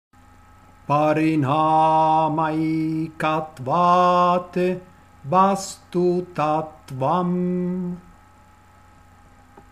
Kaivalya padah canto vedico – Yoga Saram Studio